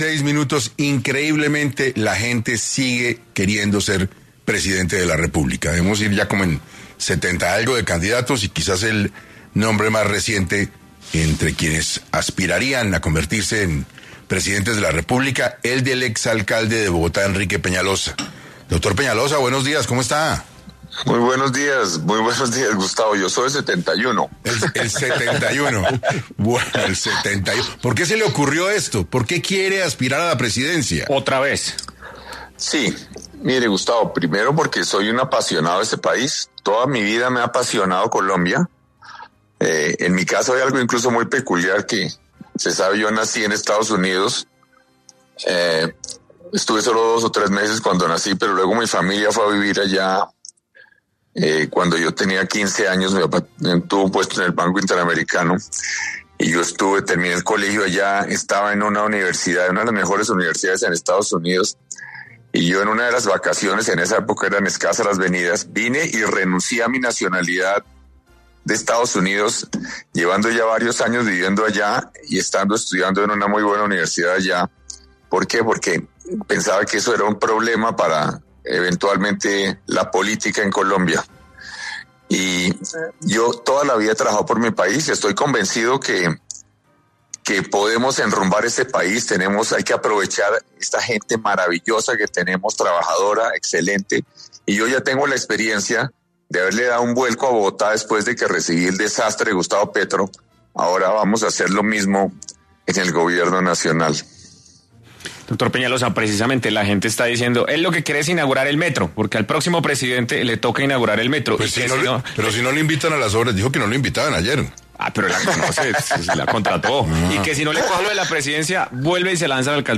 En entrevista con 6AM de Caracol Radio, el exalcalde de Bogotá, Enrique Peñalosa, confirmó su intención de aspirar a la presidencia de Colombia.